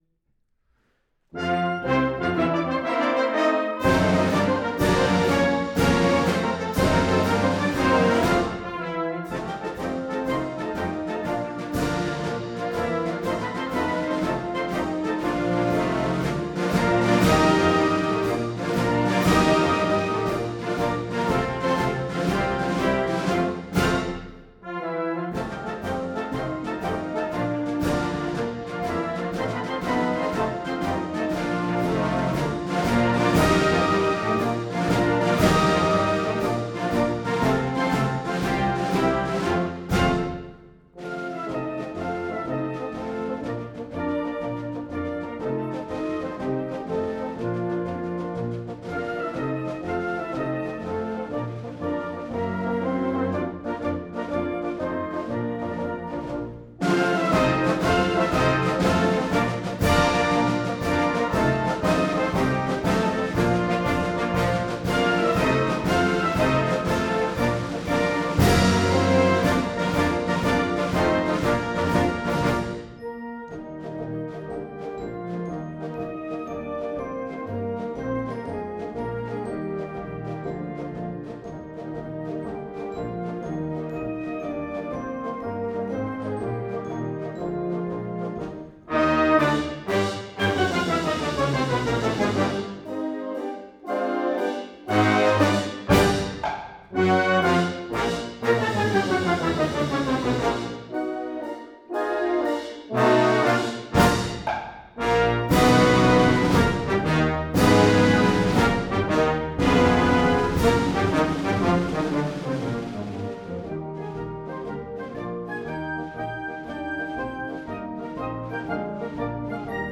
The National Game March from The Complete Marches of John Philip Sousa: Vol. 6